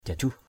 /ʤa˨˩-ʤuh˨˩/ (t.) nhăn = ridée. wrinkled. kalik jajuh kl{K jj~H da nhăn = peau ridée. wrinkled skin.